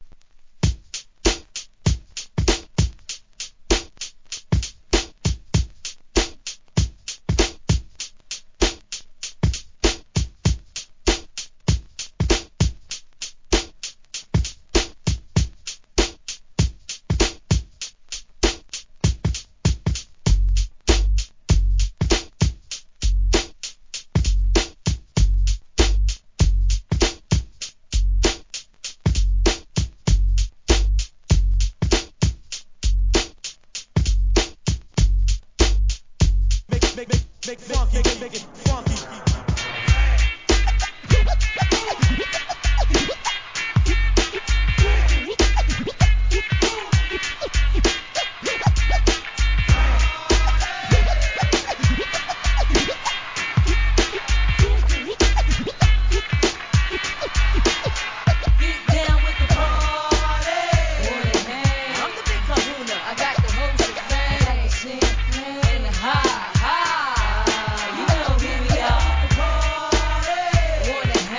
HIP HOP/R&B
(98BPM)